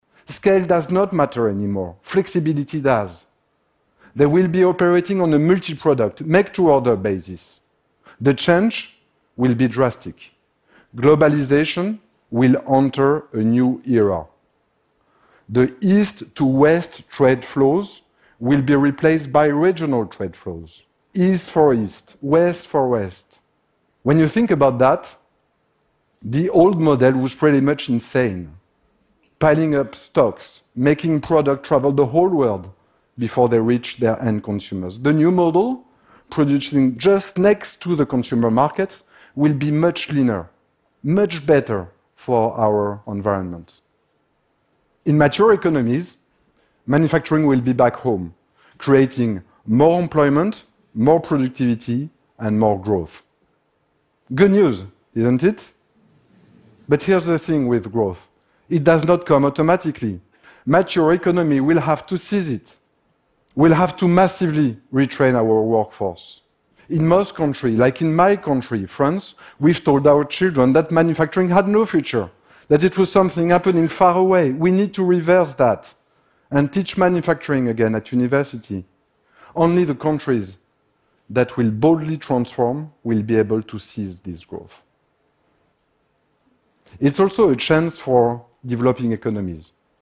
TED演讲:下一次工业革命就在眼前(7) 听力文件下载—在线英语听力室